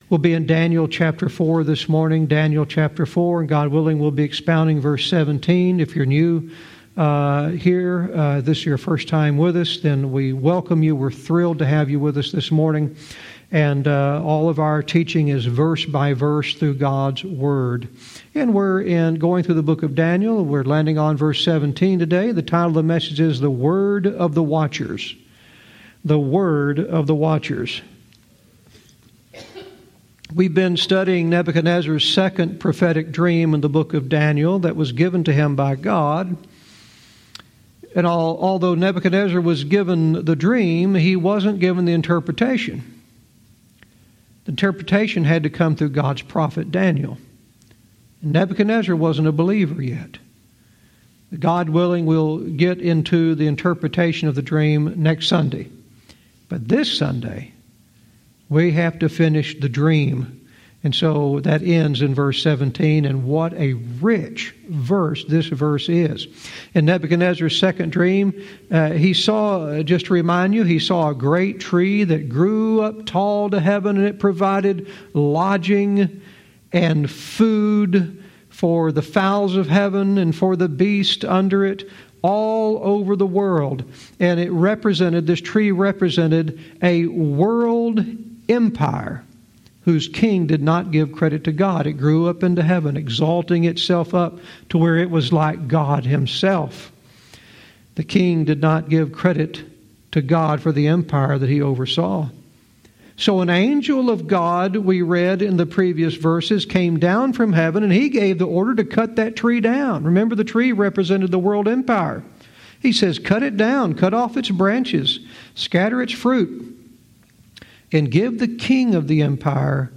Verse by verse teaching - Daniel 4:17 "The Word of the Watchers"